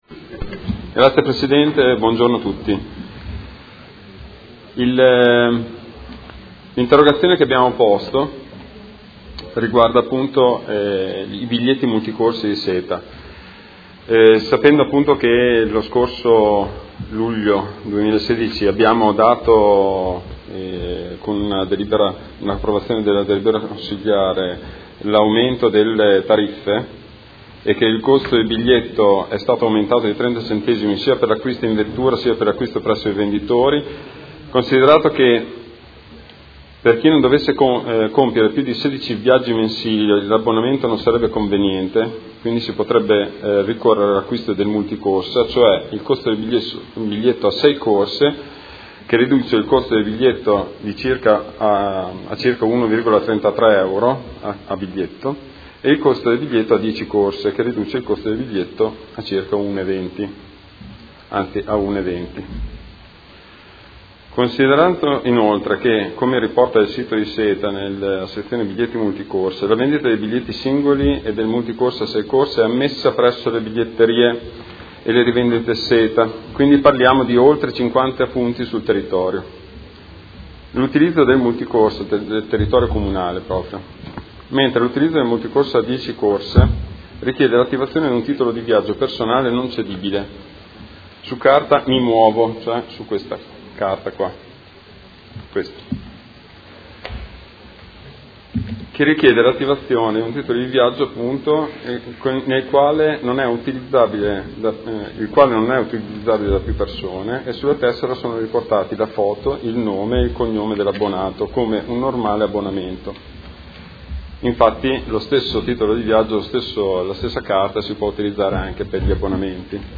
Seduta del 27/04/2017 Interrogazione del Gruppo Movimento cinque Stelle avente per oggetto: Biglietti Multicorse SETA a Modena